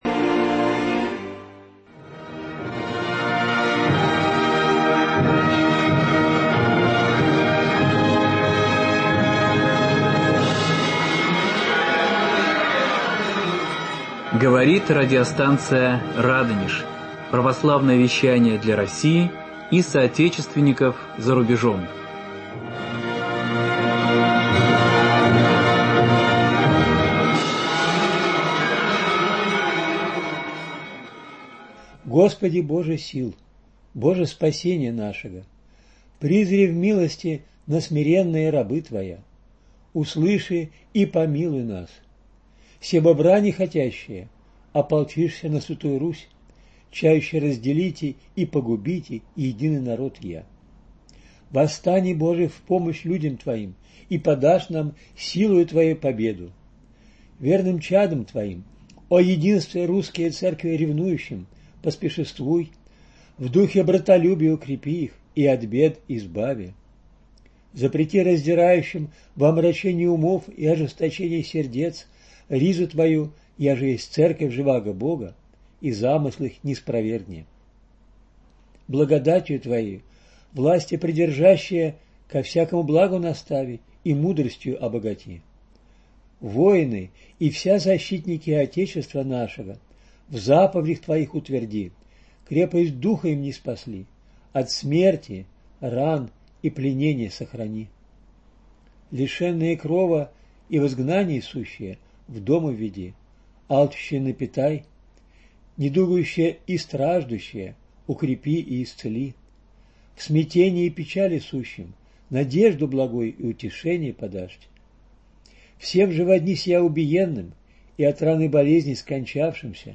Фрагменты всенощного бдения, отслуженного под праздник Богоявления в Сретенском Московском мужском монастыре